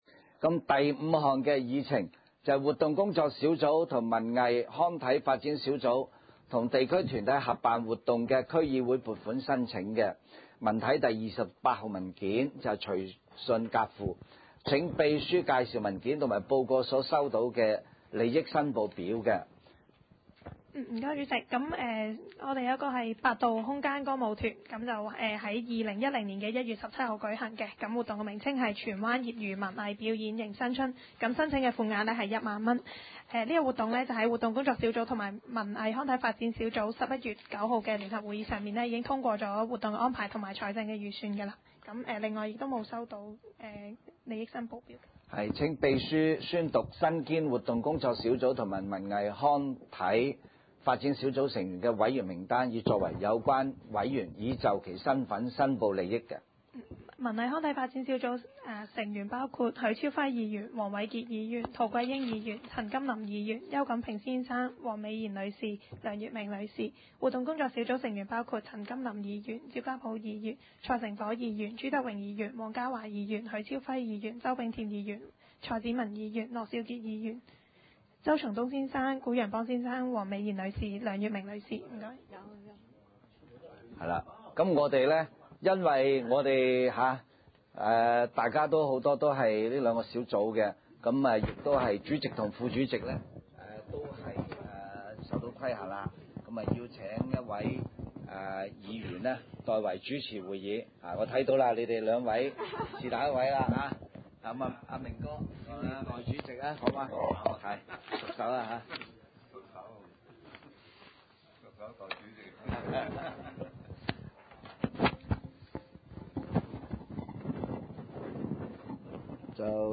文娛康樂及體育委員會第十二次會議
荃灣民政事務處會議廳